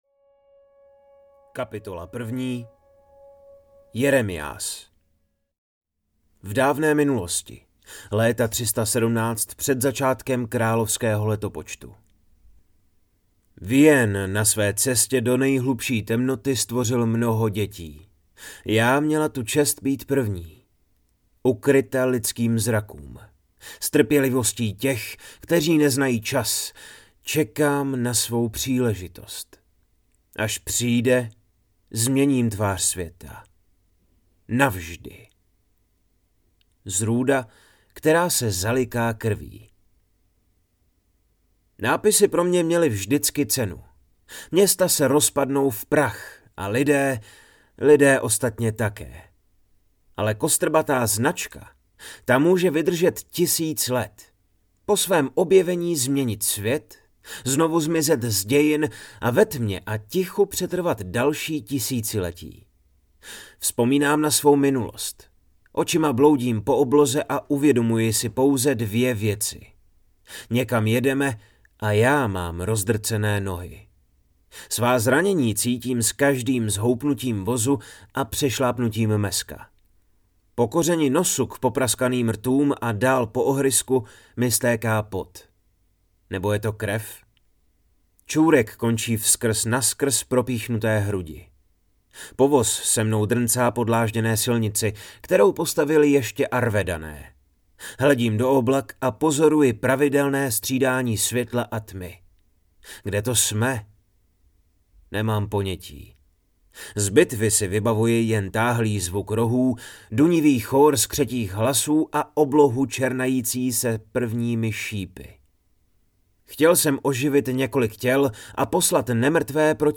Magnolie a démon audiokniha
Ukázka z knihy